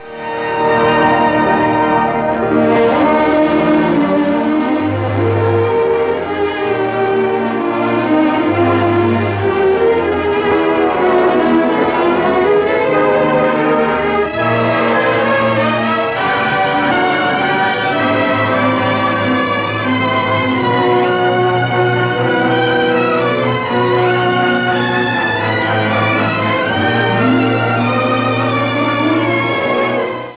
Musica
Track Music